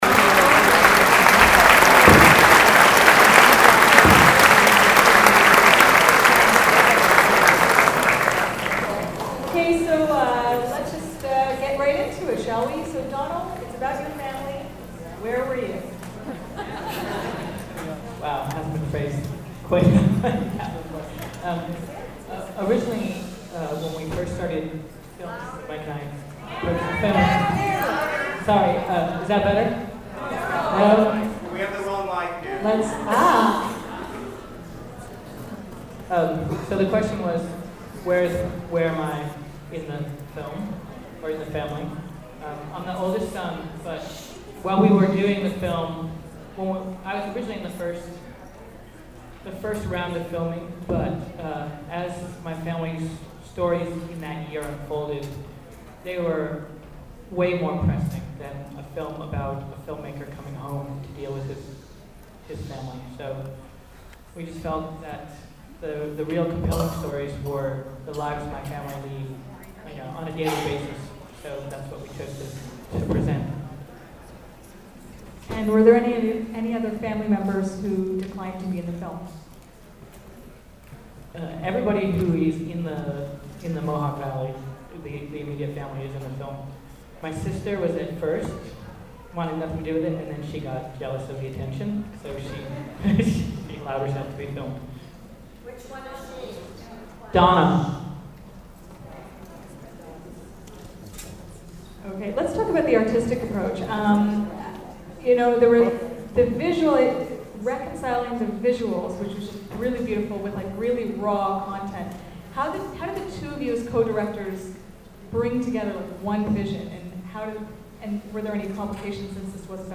octobercountry_qa.mp3